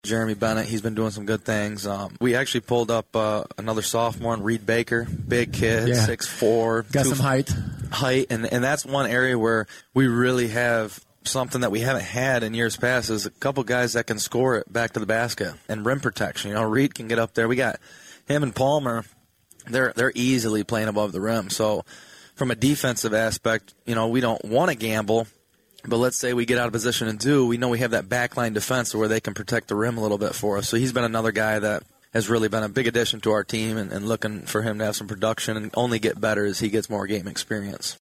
We are about two weeks away from tipping-off the high school boys basketball season. 96.5 The Cave and Lenawee TV aired their annual Coaches Preview Show live from Skytech Sports Bar on Main Street in Adrian on Sunday.